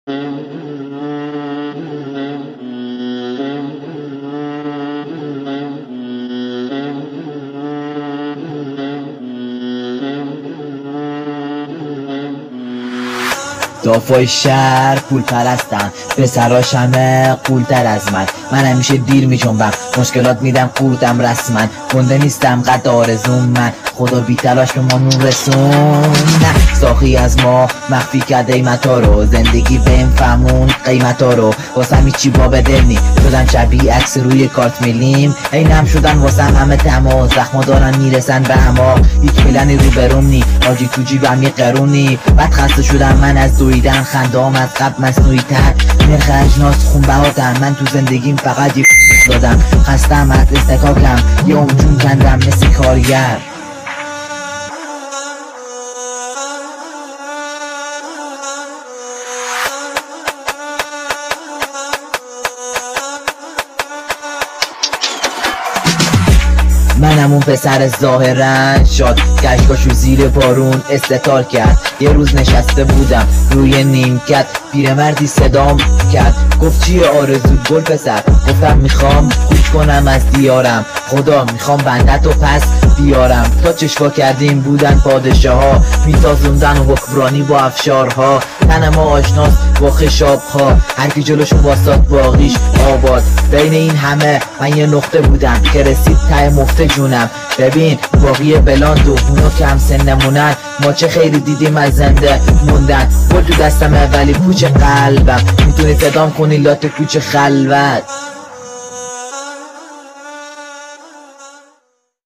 رپفاسی رپر